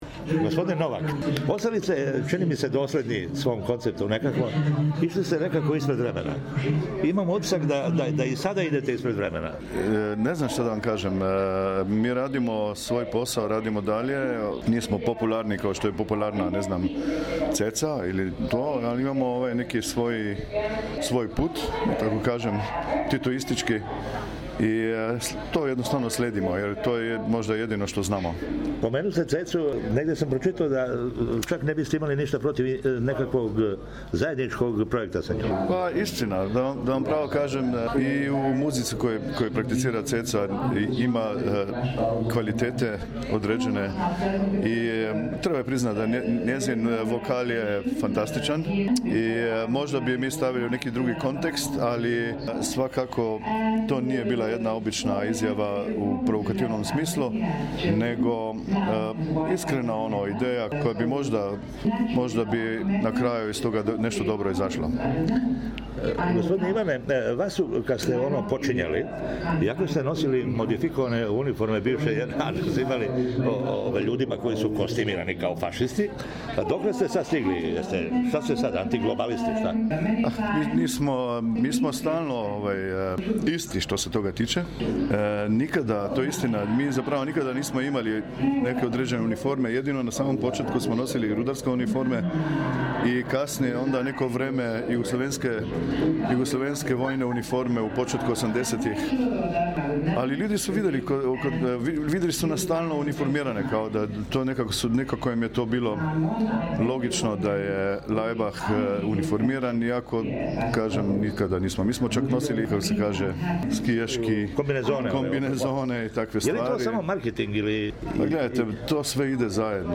У интервјуу за СБС на срспком лидер и један од оснивача контроверзне словеначке групе „Лајбах“ Иван Новак, после наступа на Међународном џез фестивалу „Нишвил“ каже да ће наставити рушење међународних граница и предрасуда тако што ће их после Северне Кореје ускоро видети и публика у Ирану где планирају наступе са државном филхармонијом те земље.